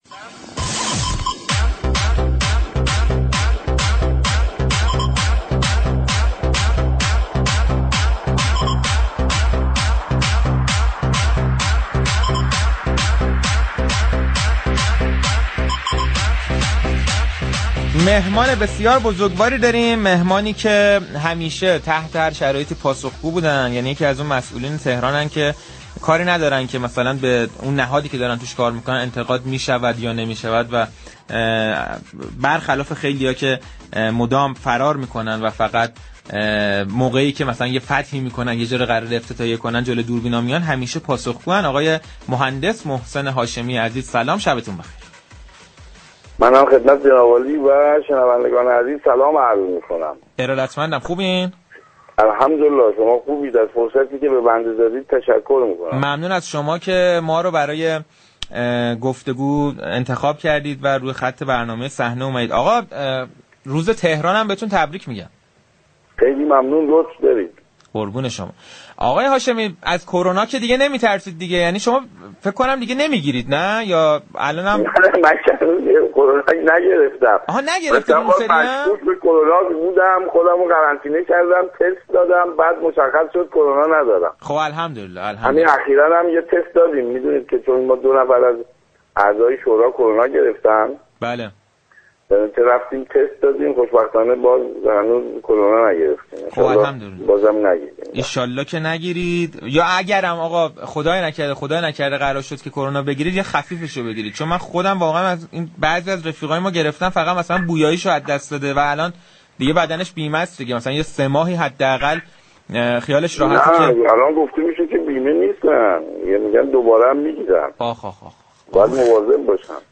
محسن هاشمی در گفتگو با برنامه صحنه رادیو تهران